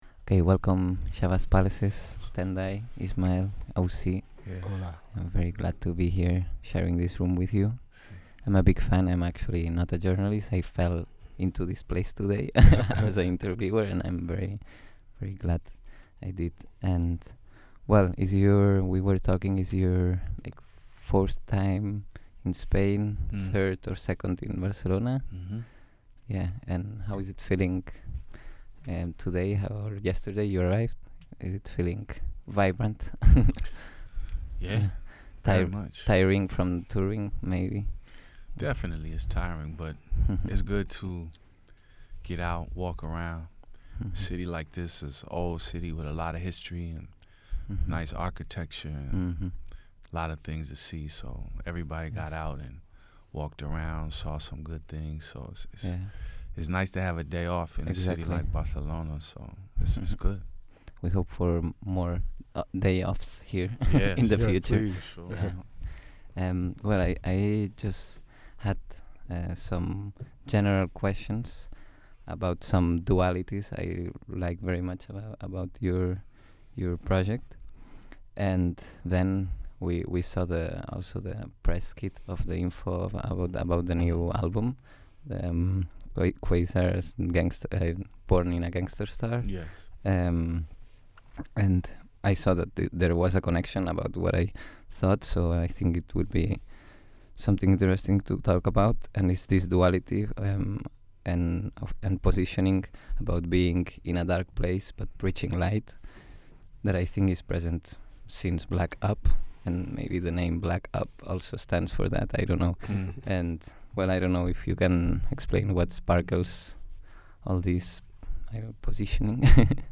Entrevista a Shabazz Palaces | Xarxa de ràdios comunitàries